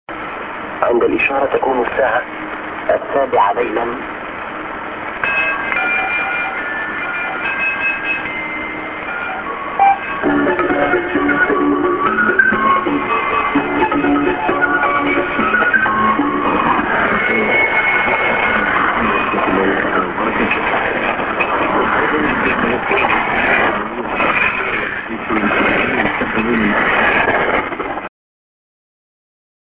ANN(men)->signal->TS->SJ->ANN(man)